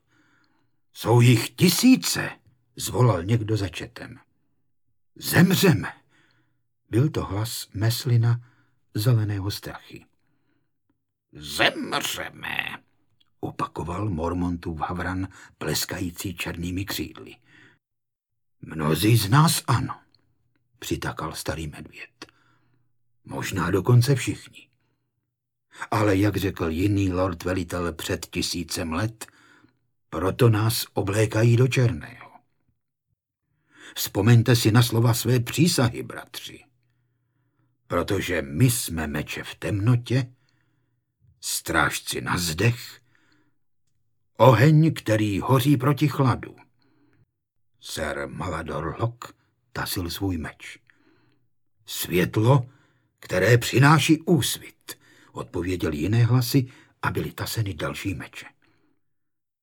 Bouře mečů audiokniha
Ukázka z knihy
• InterpretFrantišek Dočkal